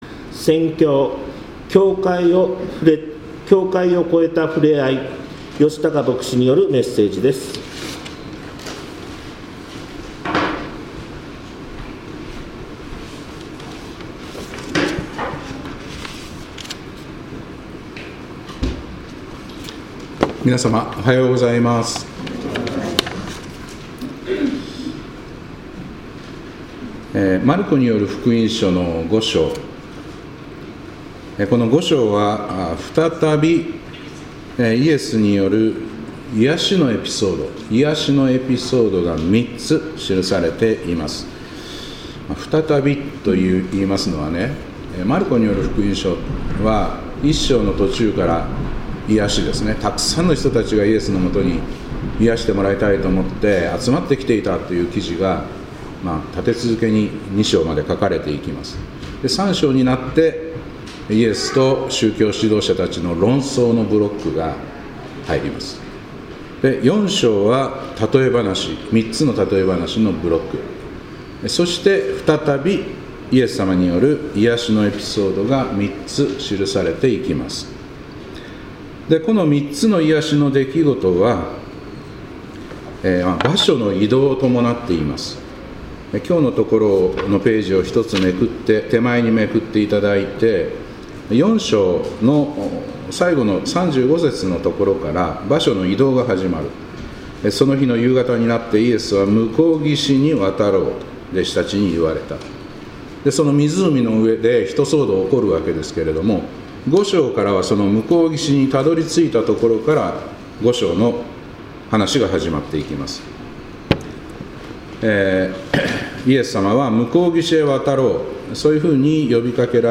2026年2月1日礼拝「境界を越えたふれあい」